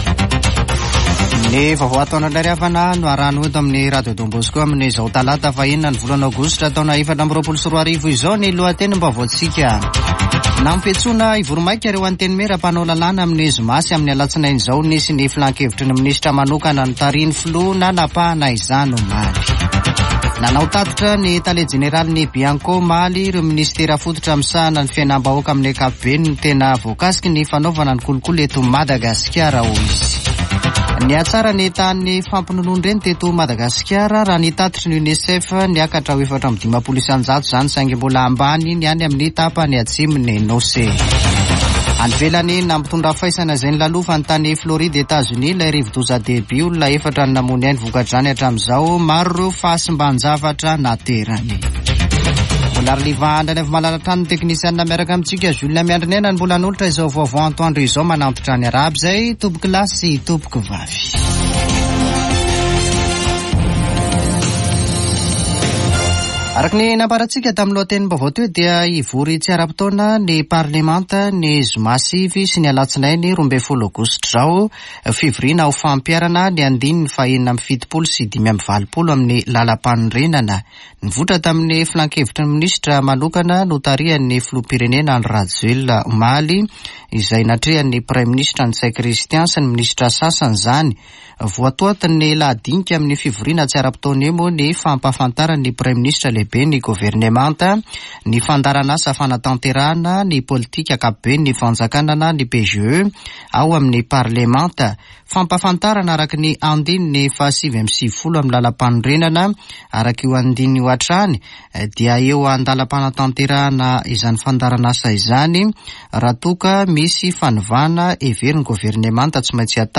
[Vaovao antoandro] Talata 6 aogositra 2024